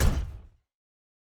Footstep Robot Large 2_06.wav